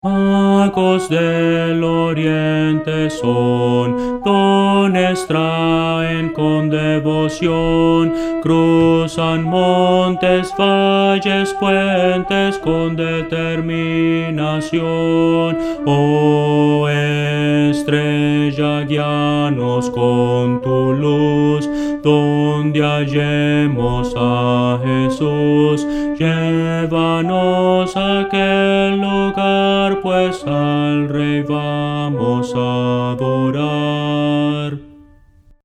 Voces para coro
Contralto – Descargar